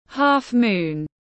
Hình trăng khuyết tiếng anh gọi là half-moon, phiên âm tiếng anh đọc là /ˌhɑːfˈmuːn/.
Half-moon /ˌhɑːfˈmuːn/